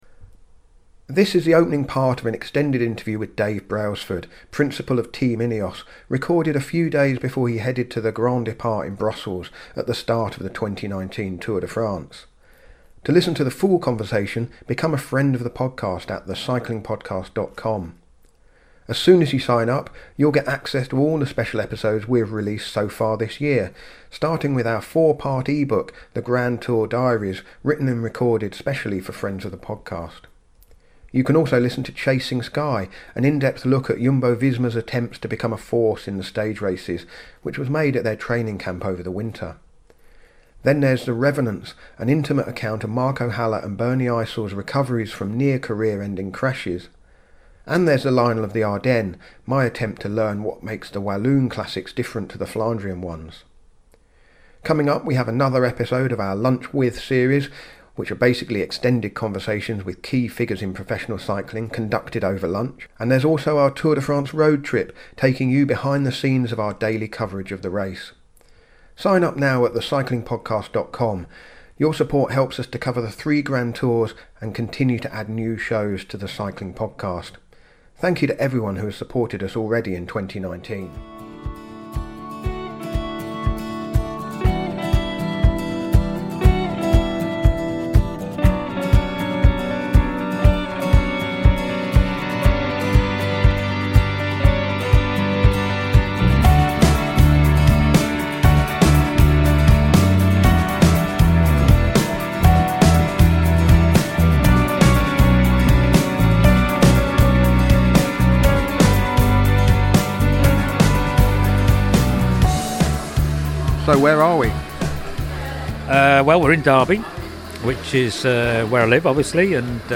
In celebration of the end of the Tour de France, we've putting the first 15 minutes of this extended interview here for all of The Cycling Podcast listeners to enjoy.